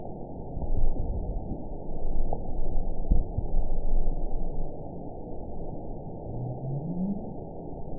event 921796 date 12/19/24 time 03:38:35 GMT (4 months, 2 weeks ago) score 9.41 location TSS-AB03 detected by nrw target species NRW annotations +NRW Spectrogram: Frequency (kHz) vs. Time (s) audio not available .wav